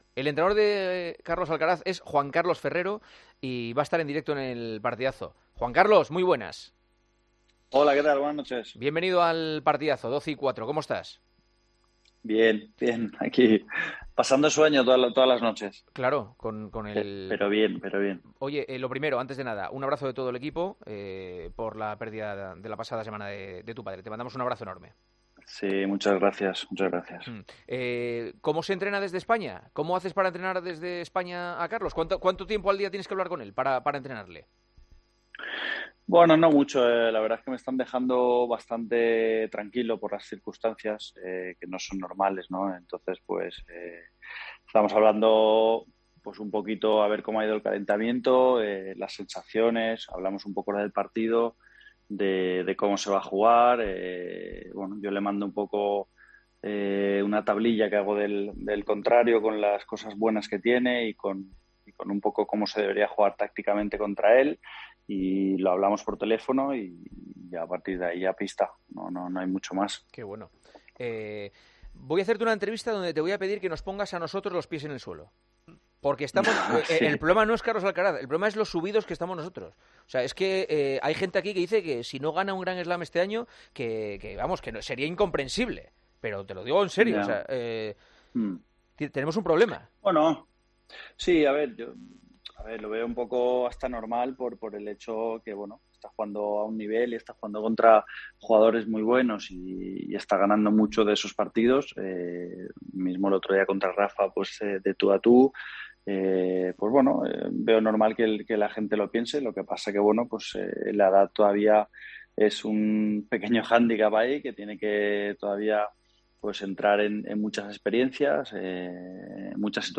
AUDIO: Juan Carlos Ferrero contó en con detalle El Partidazo de COPE en qué momento está su 'pupilo' Alcaraz, la sensación tenística del momento:...